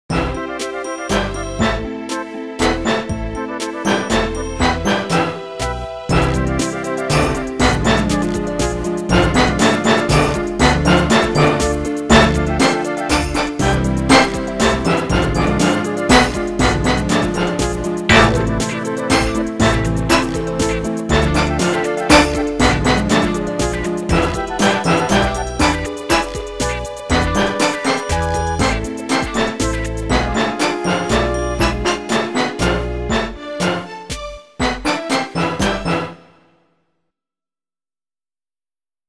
【拍子】4/4 【種類】 【演奏】火打ち石の音が入ってます。